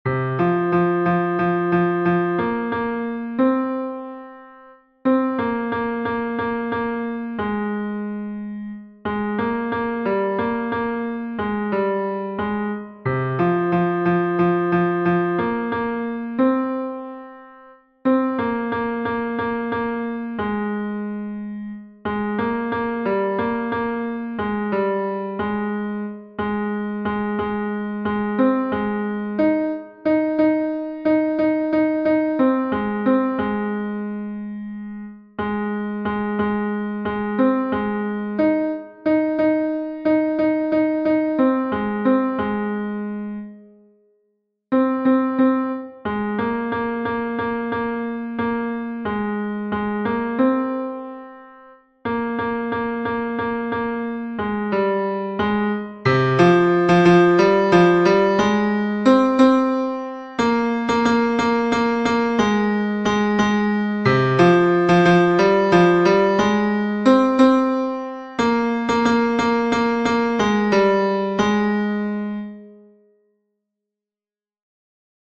Fichier son ténor 2
Nerea-izango-zen-Laboa-tenor-2-V3-1.mp3